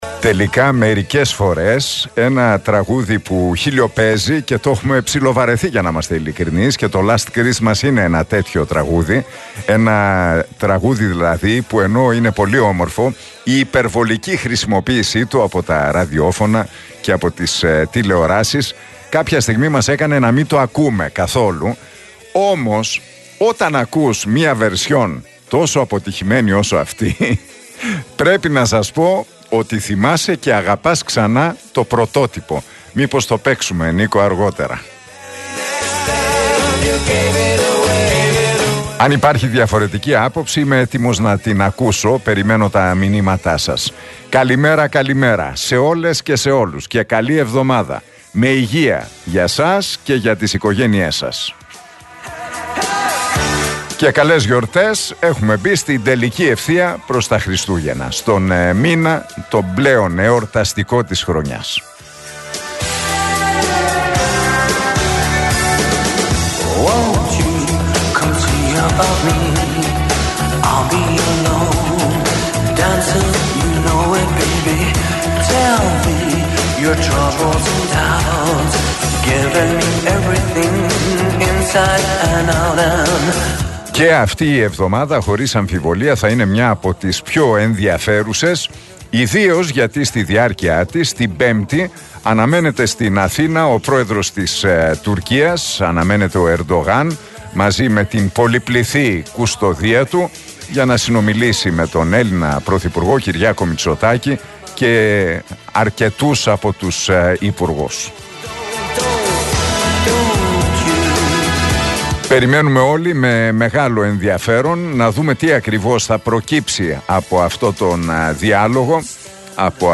Ακούστε το σχόλιο του Νίκου Χατζηνικολάου στον RealFm 97,8, την Δευτέρα 4 Δεκεμβρίου 2023.